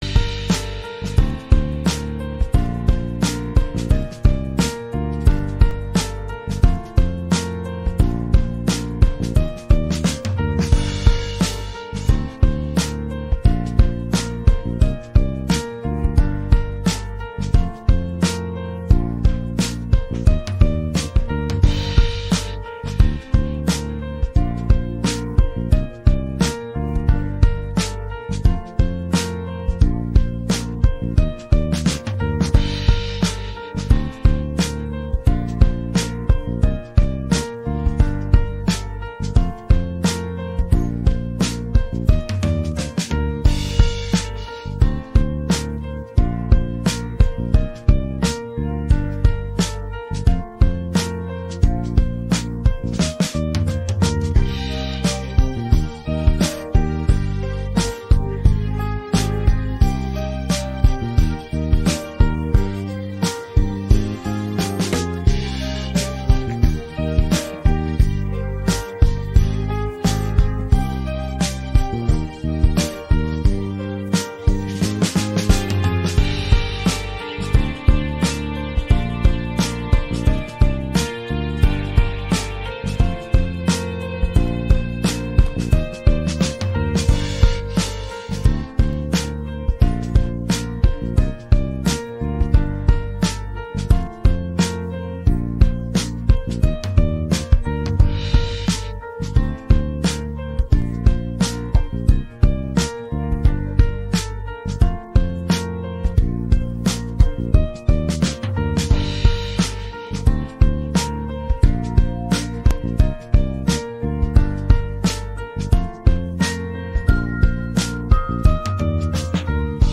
pop rock караоке 16
Українські хіти караоке